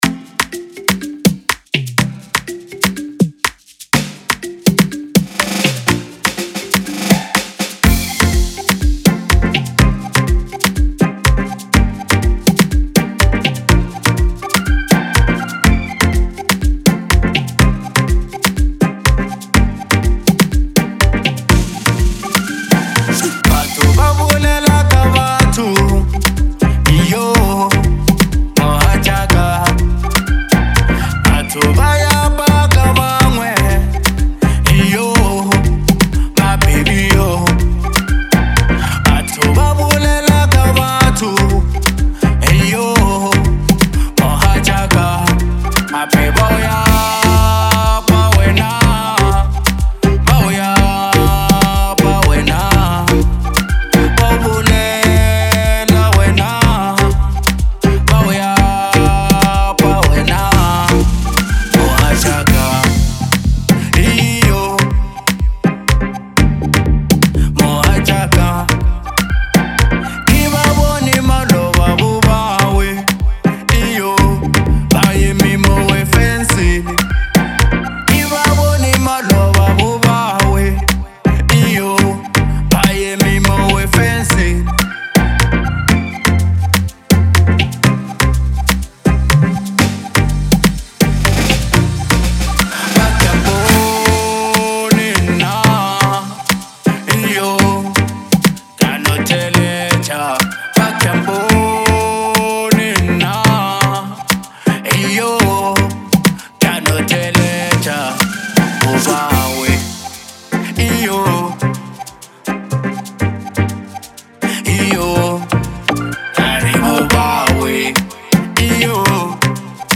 enegergic bolo house single